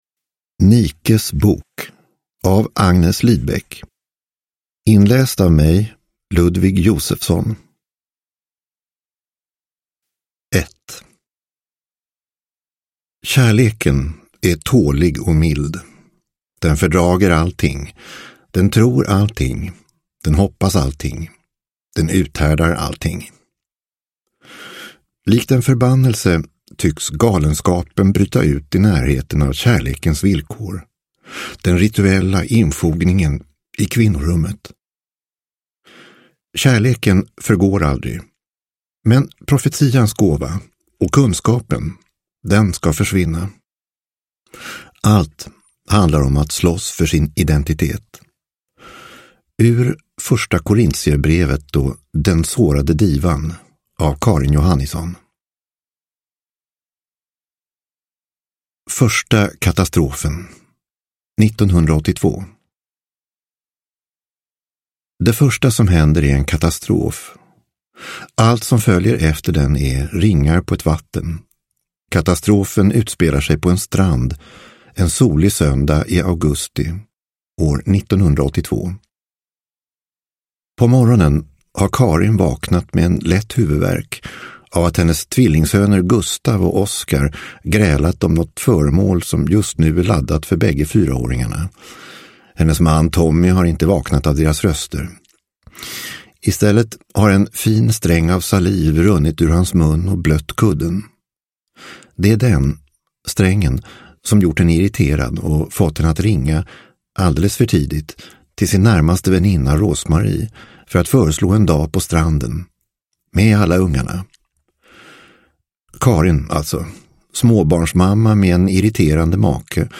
Nikes bok – Ljudbok – Laddas ner